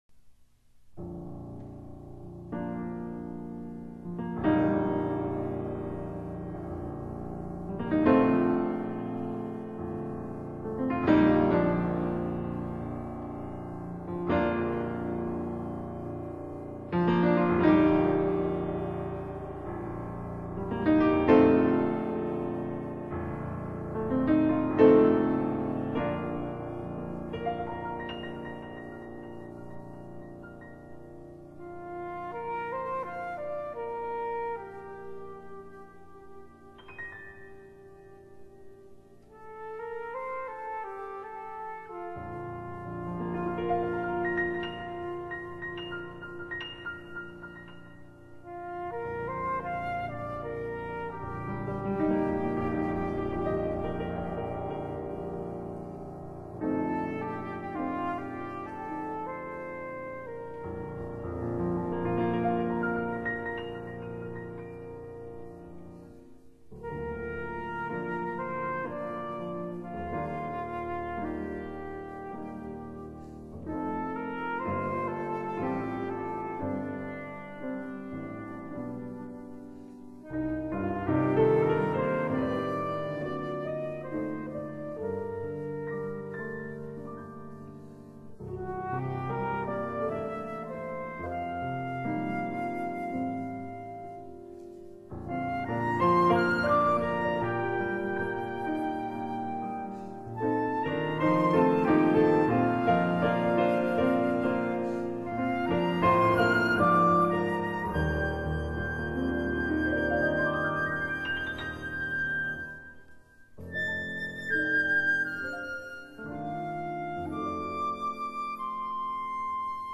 (長笛與鋼琴作品) (320K/mp3)
如此張合奏般乾淨及簡潔的樂音，在試聽時既會讓雜音無所遁形，也不會因而産生雜音。